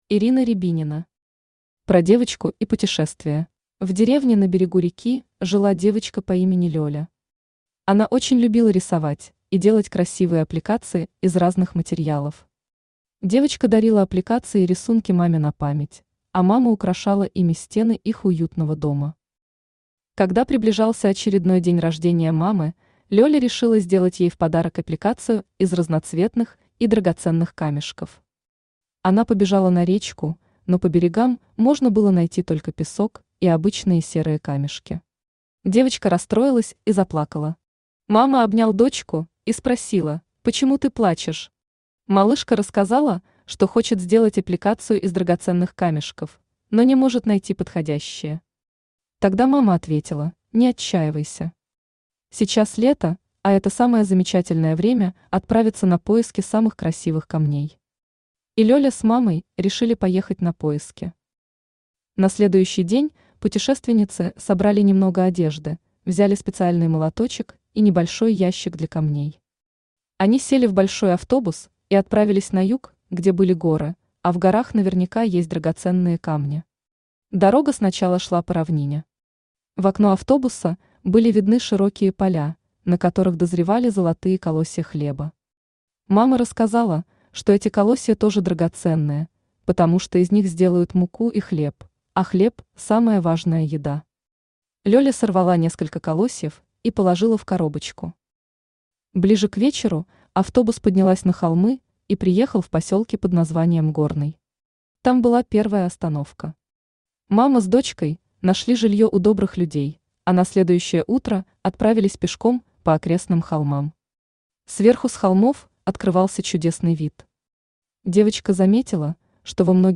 Аудиокнига Про девочку и путешествие | Библиотека аудиокниг